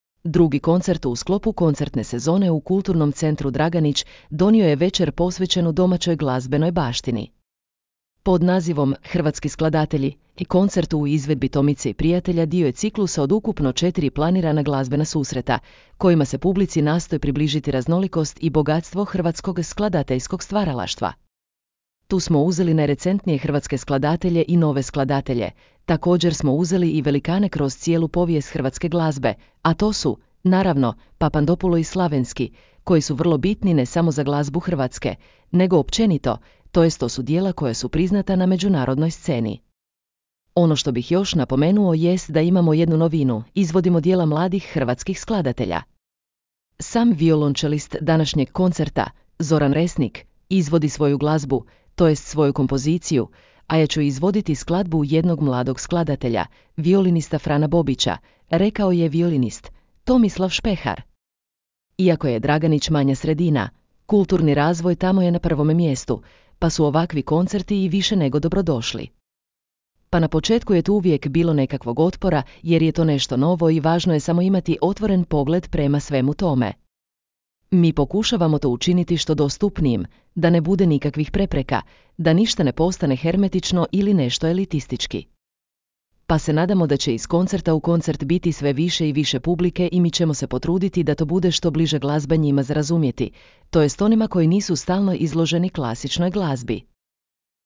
Drugi koncert u sklopu koncertne sezone u Kulturnom centru Draganić donio je večer posvećenu domaćoj glazbenoj baštini.